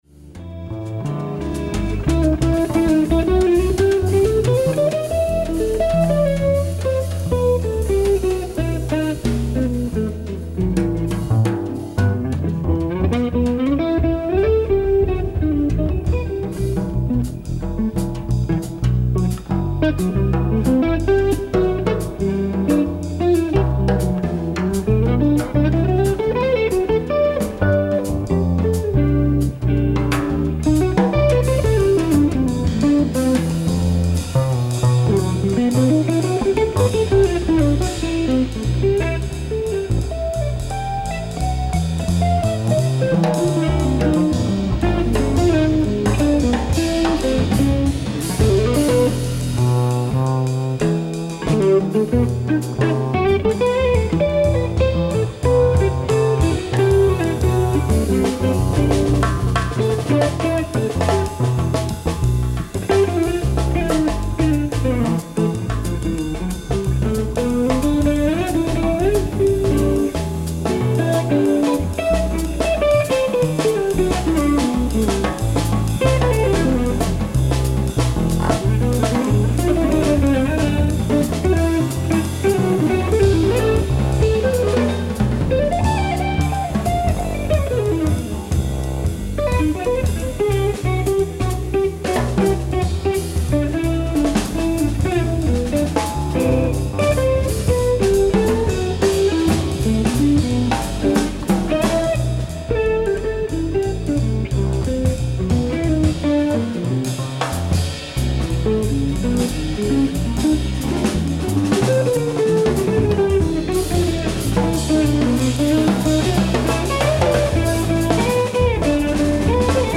ライブ・アット・チューリッヒ、スイス 10/22/2004
※試聴用に実際より音質を落としています。